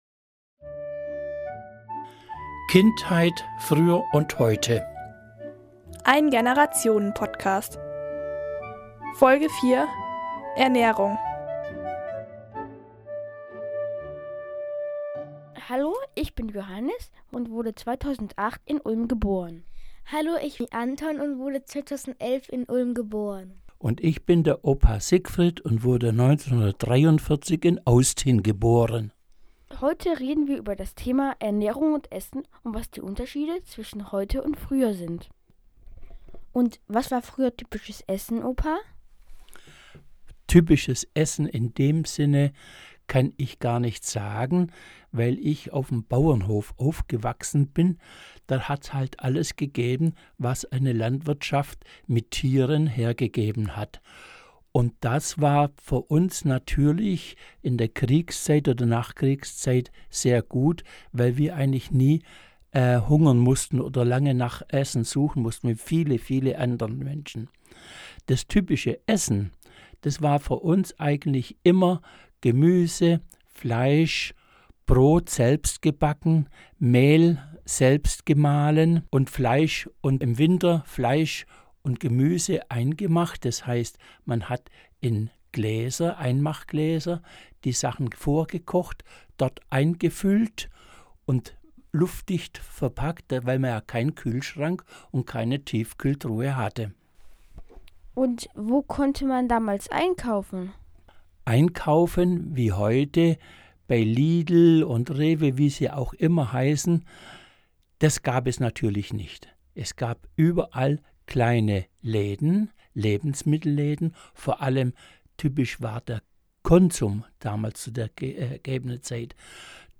Ulmer Radio von Kindern für Kinder
In den Sommerferien haben sich vier intergenerationelle Teams in